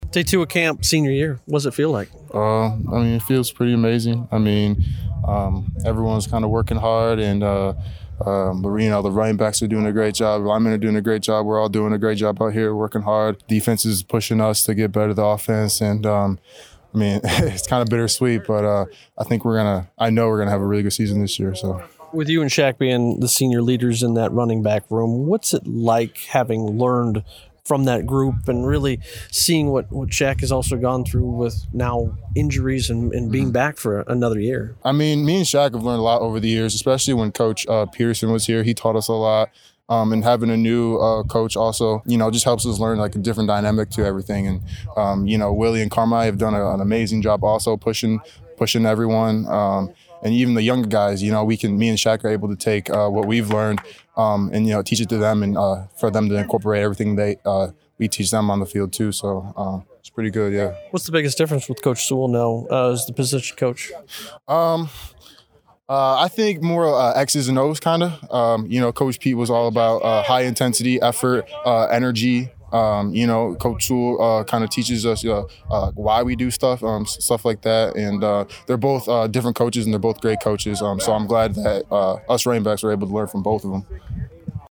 Post-Practice Audio: